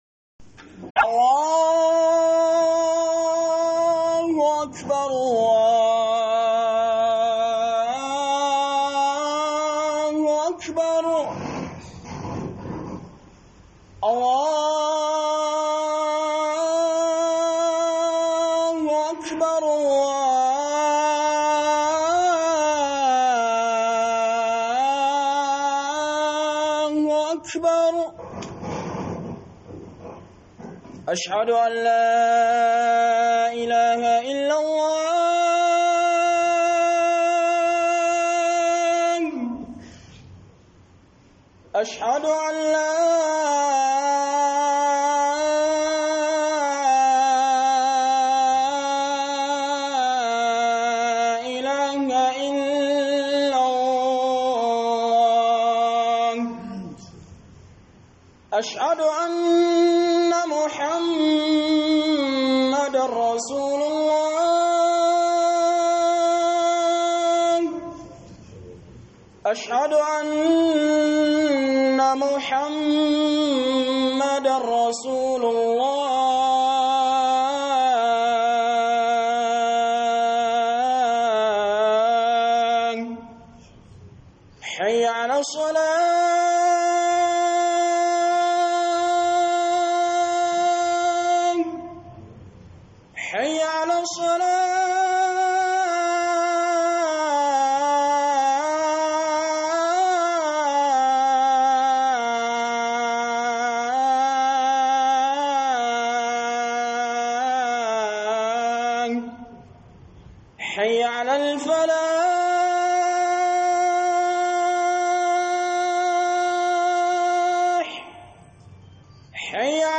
AMANA - Huduba